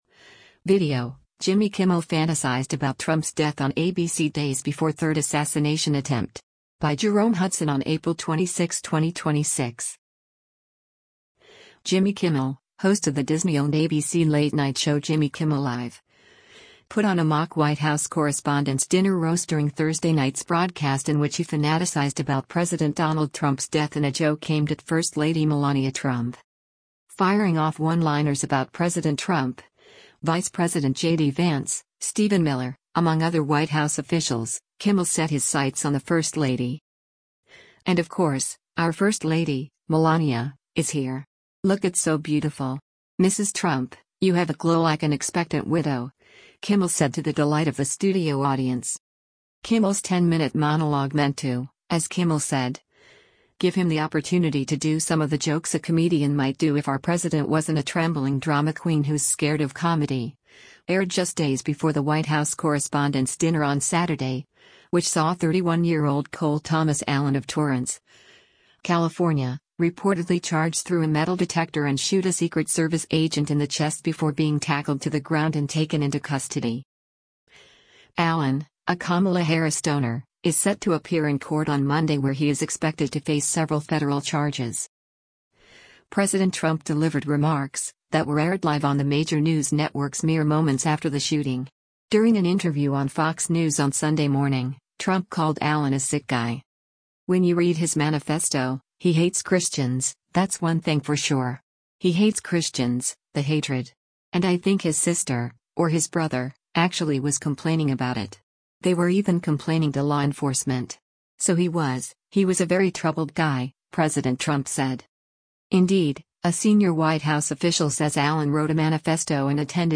Jimmy Kimmel, host of the Disney-owned ABC late-night show Jimmy Kimmel Live!, put on a mock White House Correspondents’ Dinner roast during Thursday night’s broadcast in which he fanaticized about President Donald Trump’s death in a joke aimed at First Lady Melania Trump.
“And of course, our first lady, Melania, is here. Look at So beautiful. Mrs. Trump, you have a glow like an expectant widow,” Kimmel said to the delight of the studio audience.